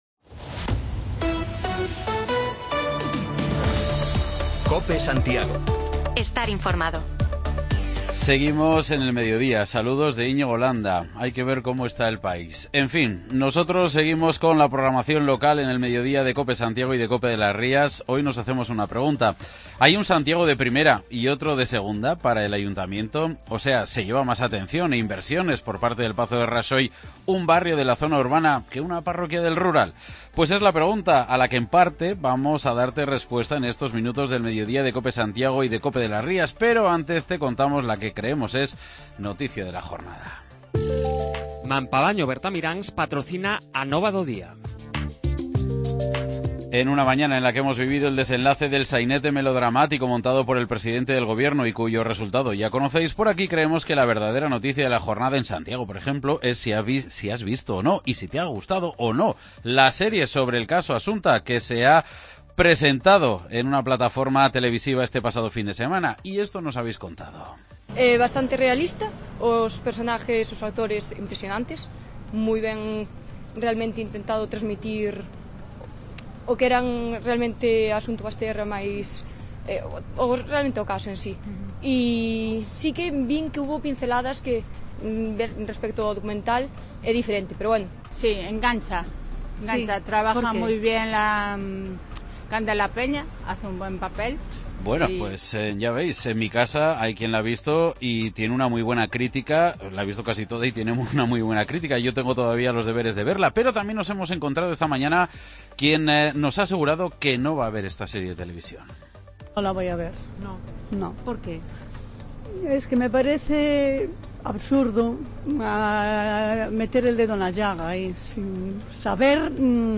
Un vecino de Rial de Conxo nos cuenta la pelea que llevan manteniendo con el ayuntamiento desde hace meses para que les solucionen los problemas con el suministro de agua: este fin de semana, del grifo de muchas casas salió un líquido turbio, supuestamente potable